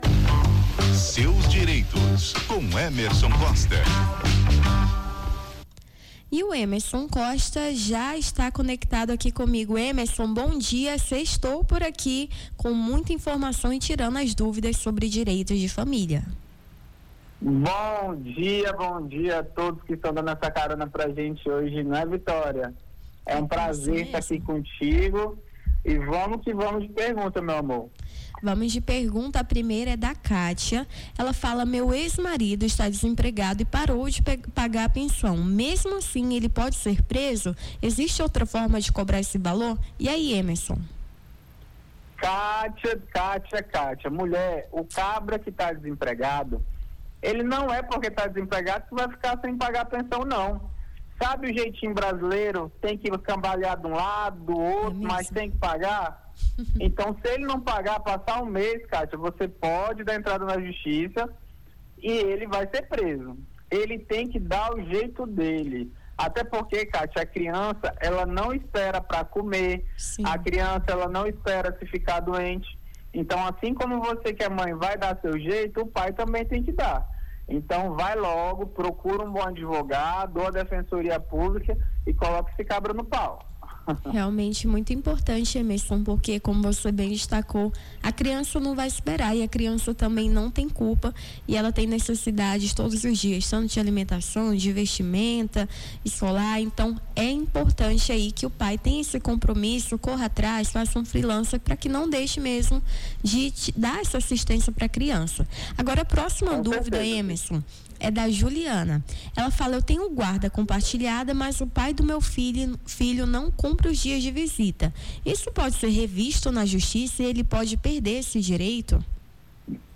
Seus Direitos: advogado tira dúvidas sobre direito de família